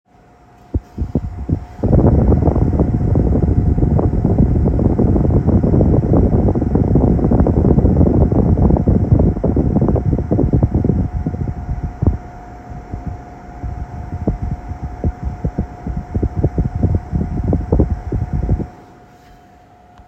Bruit aigu vmc double flux
Bonjour, J'ai install�, il y a maintenant 4 ans une VMC MAICO WS320. Depuis quelques temps, j'ai un bruit aigu qui est apparu.
Le bruit est toujours pr�sent donc le souci provient du bloc VMC et non du r�seau.